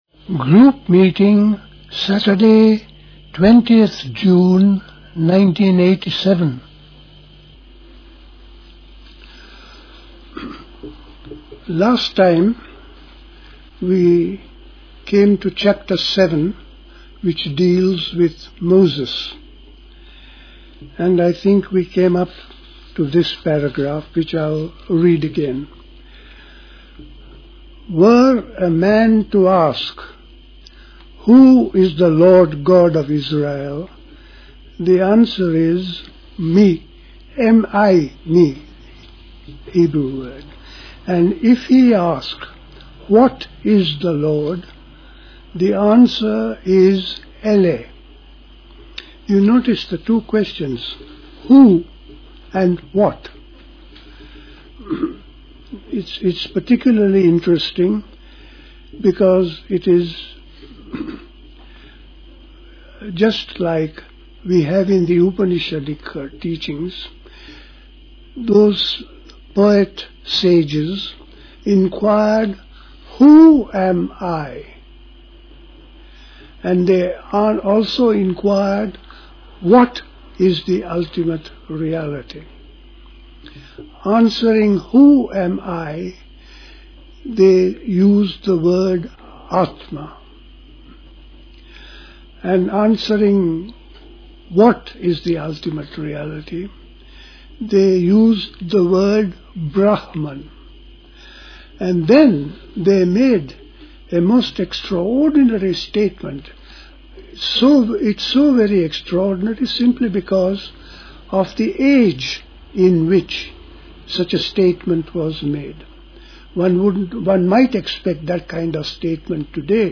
A talk
at Dilkusha, Forest Hill, London